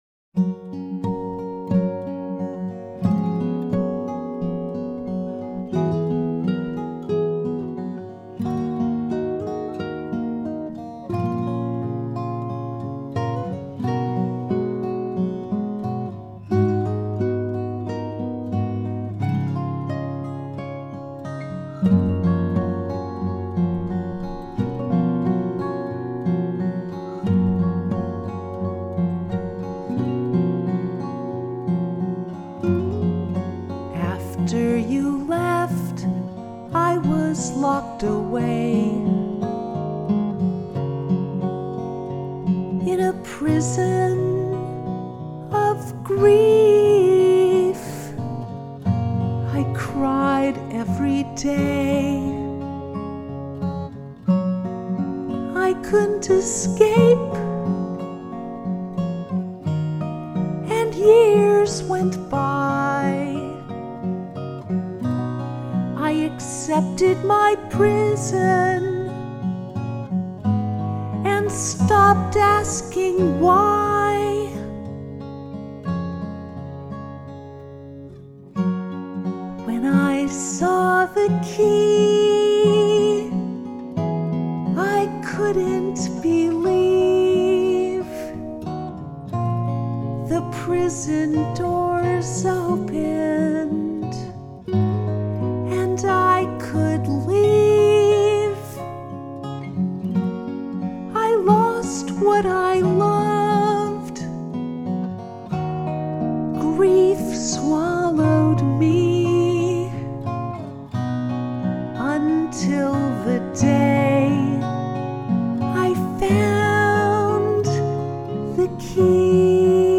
The Key Vocal & Arrangement
the-key-gtr-voc-gtr-7-16-25.mp3